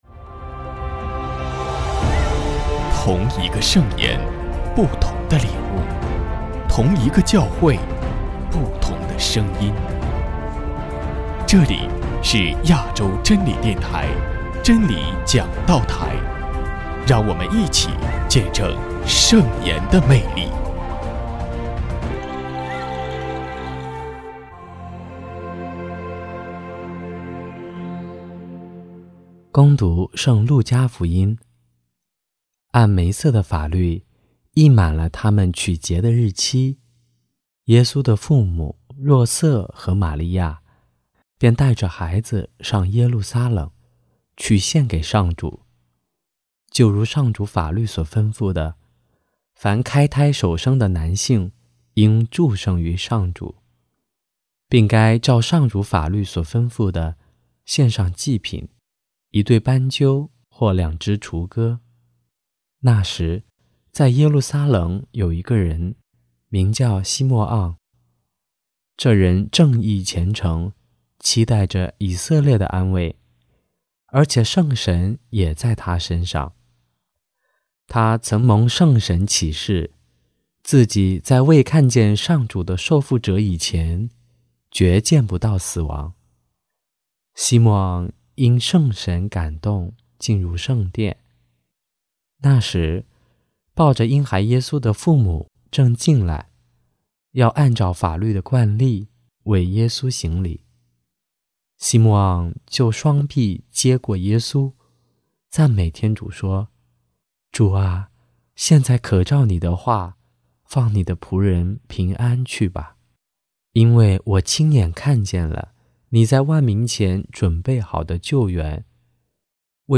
——基督的福音 证道： 1.